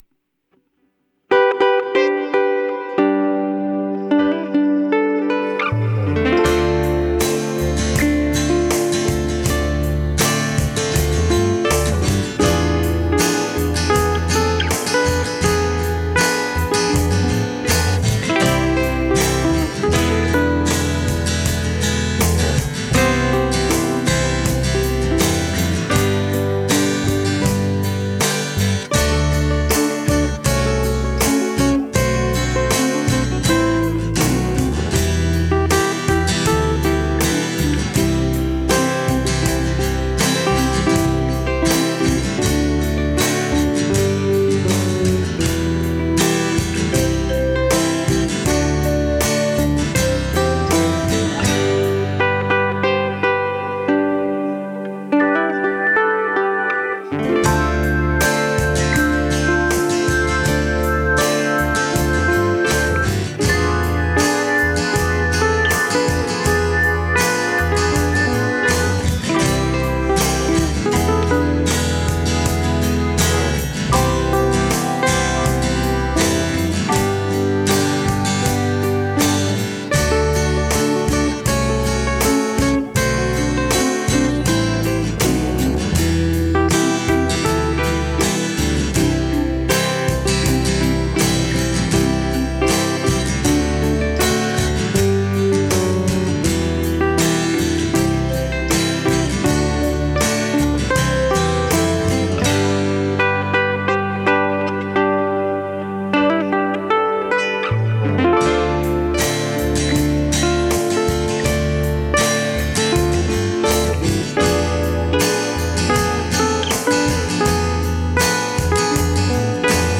Bass and keys
orchestral flavor